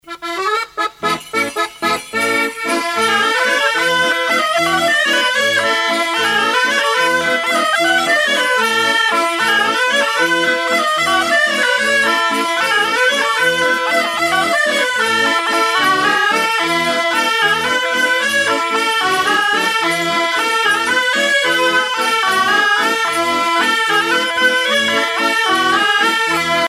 bourrée
danse : bourree
Pièce musicale éditée